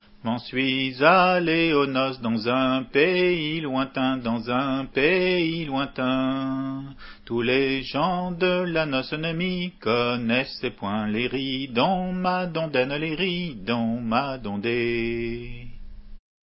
Entendu à Ti ar Vretoned Mission bretonne en janvier 89